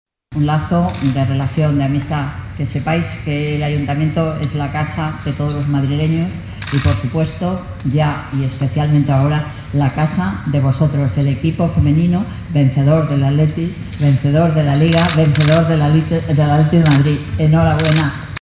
Hoy, martes 30 de mayo, en el Salón Institucional del Ayuntamiento de Madrid
Nueva ventana:Manuela Carmena, alcaldesa de Madrid